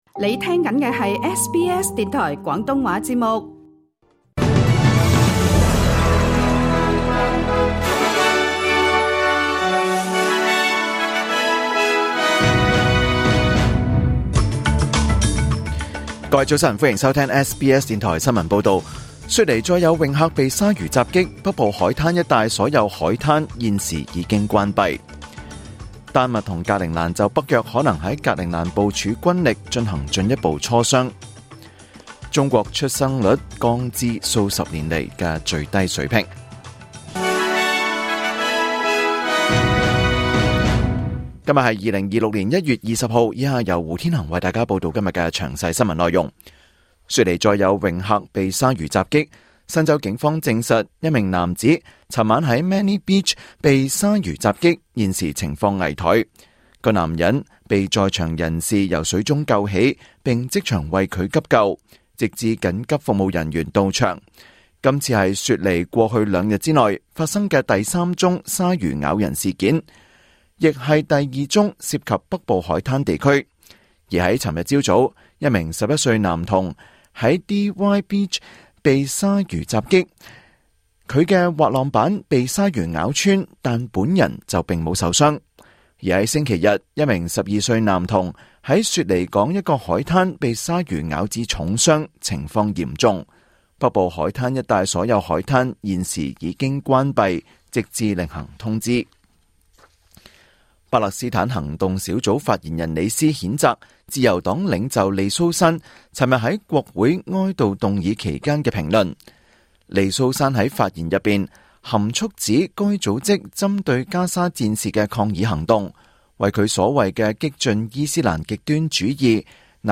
2026年1月20日SBS廣東話節目九點半新聞報道。